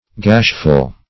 Search Result for " gashful" : The Collaborative International Dictionary of English v.0.48: Gashful \Gash"ful\, a. Full of gashes; hideous; frightful.